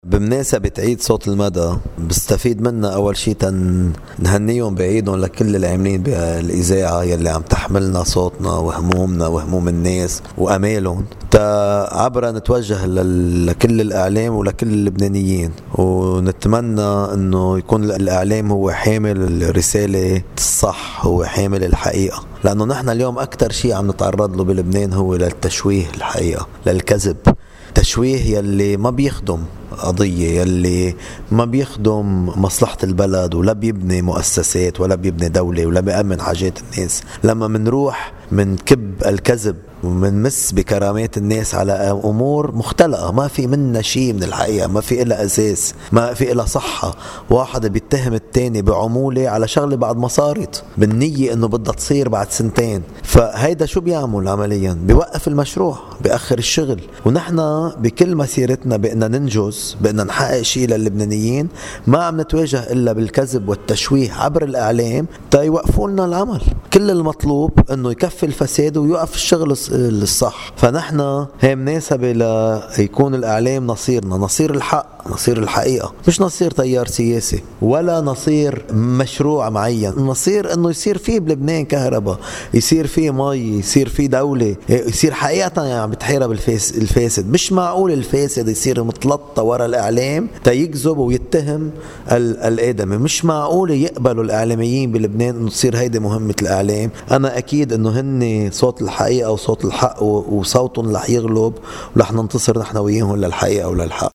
رئيس التيار الوطني الحرّ جبران باسيل في عيد “صوت المدى”: